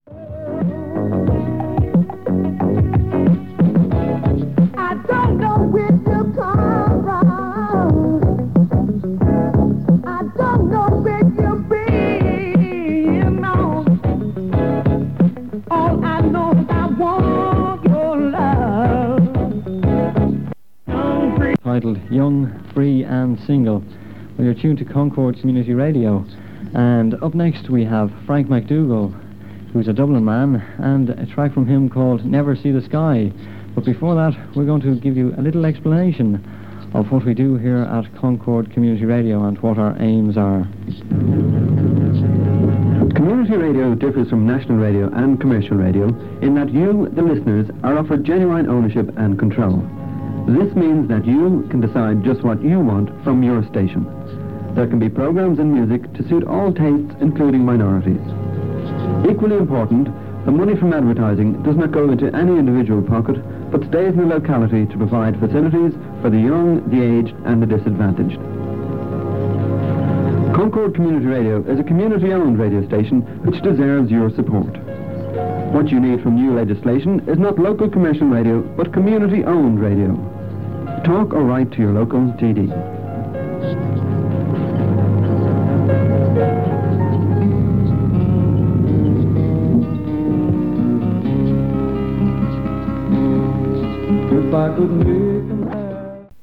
This short recording of Concord Community Radio includes an unidentified DJ and a promo outlining the philosophy of community radio, reflecting the political debate about the licensing of local radio at that time. It was made from 891 kHz AM on Saturday 21st May 1983, just days after the raids on the large commercial Dublin stations Radio Nova and Sunshine Radio.